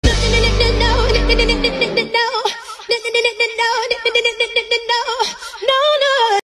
Jessie J ‘no’ meme sound effects free download